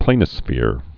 (plānĭ-sfîr)